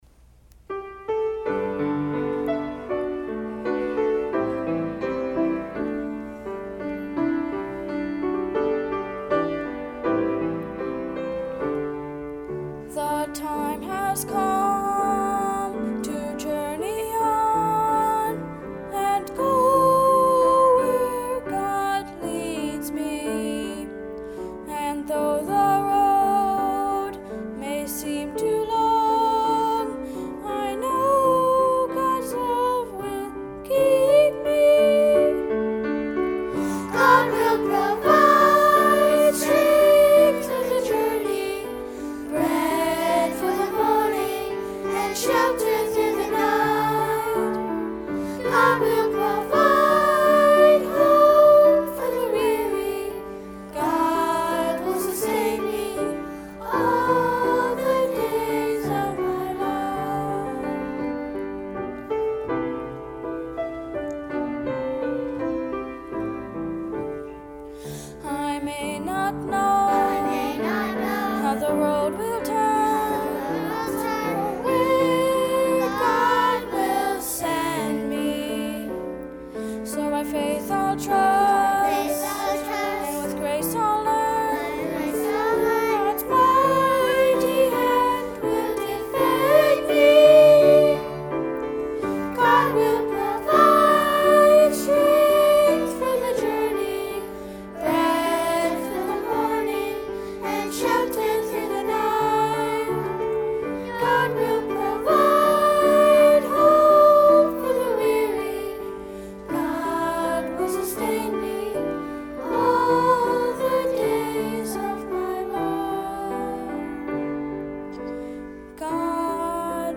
Junior Choir
piano